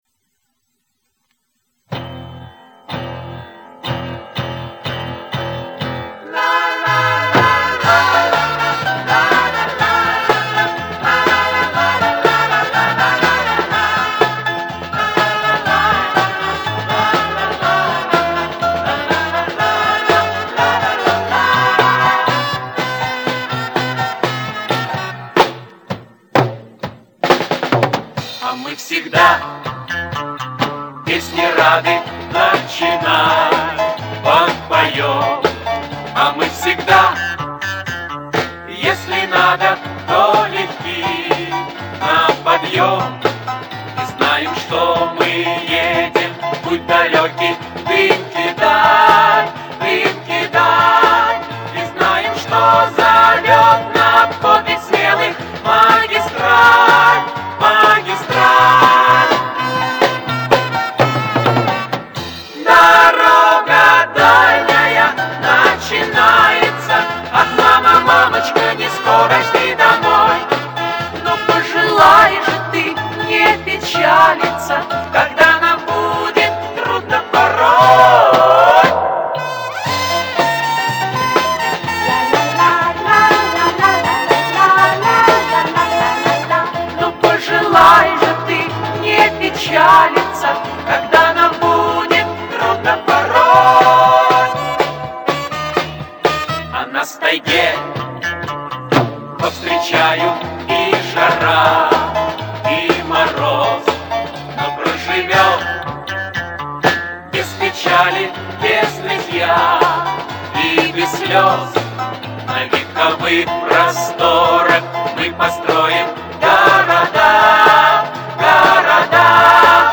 Вот еще прибавил 3 децибела, чтобы у Вас был выбор